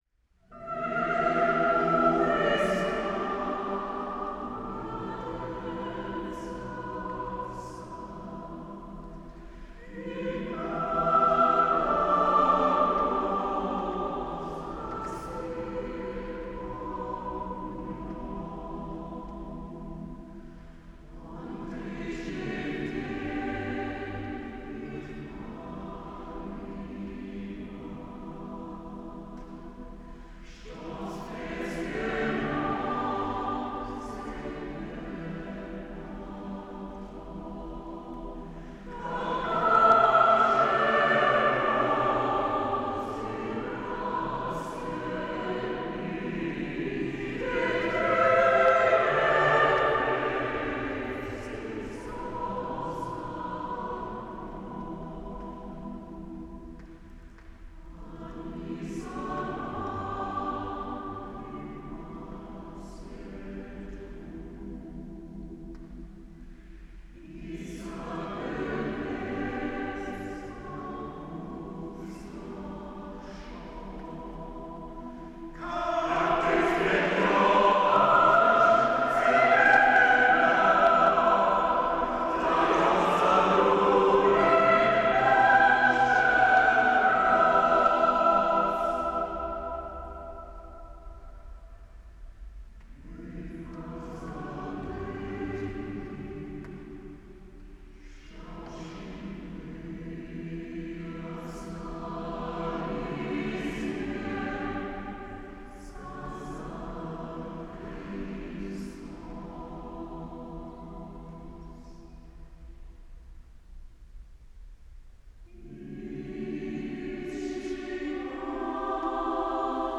Diese sind Mitschnitte aus verschiedenen Konzerten.
aufgeführt am 13. Juli 2007 in der Gethsemane-Kirche